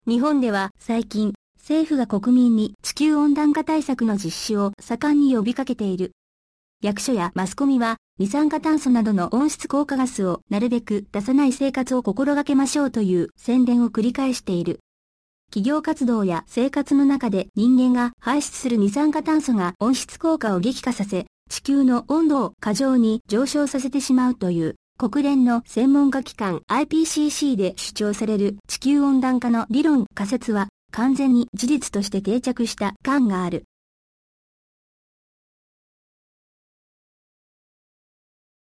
I chose female voices because I found them easier to understand.
The Japanese voices are especially blowing me away [
audio sample of the first paragraph of this article].